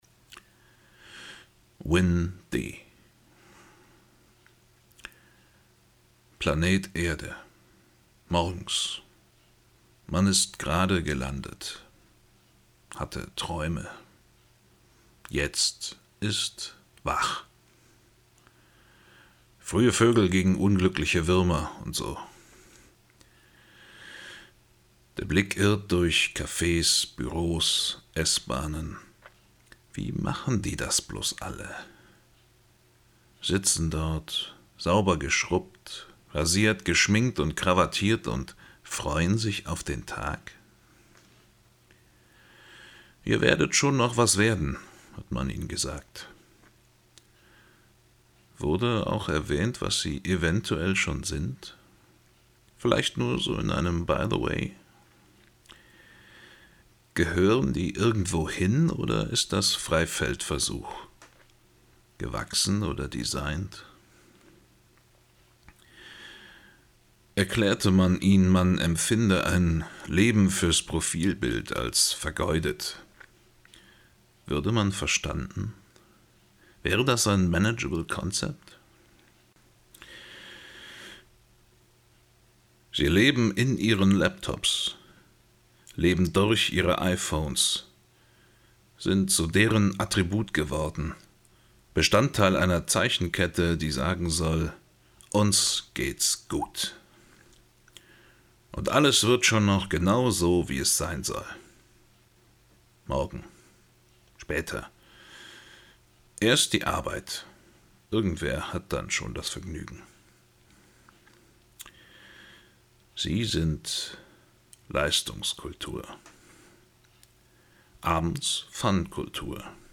Hier wird gelesen.